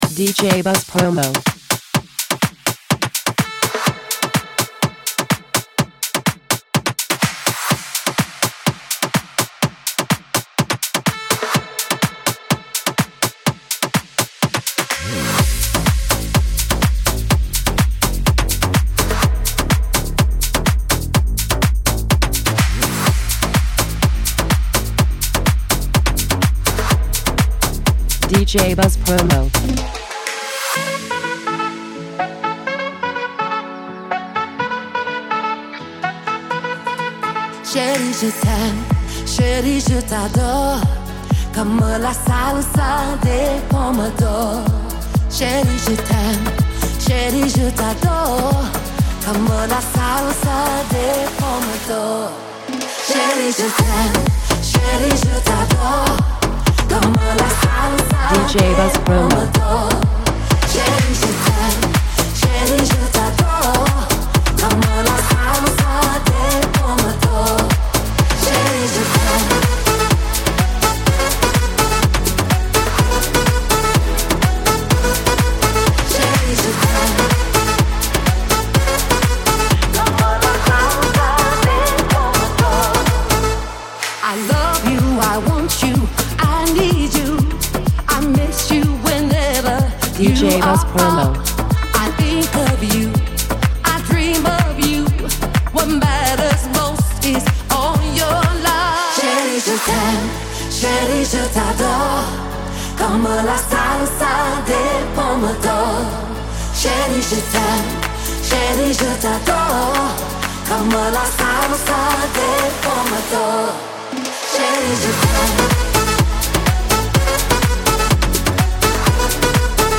Saxy track!
electro